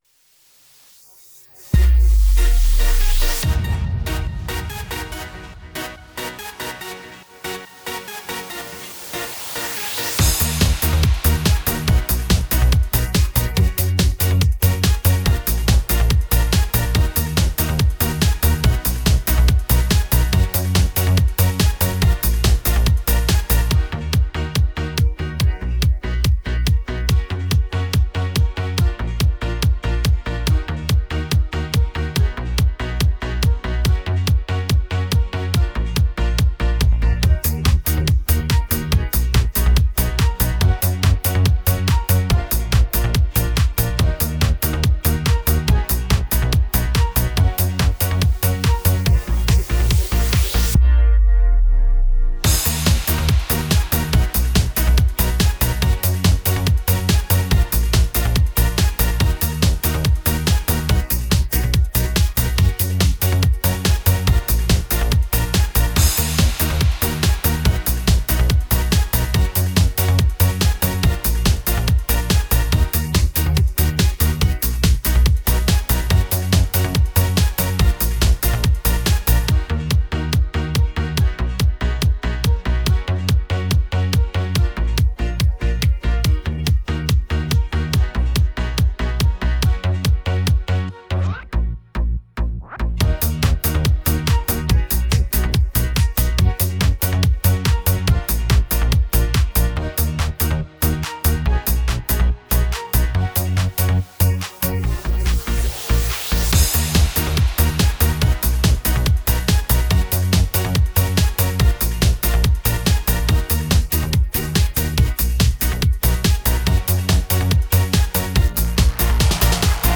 минус песни (караоке)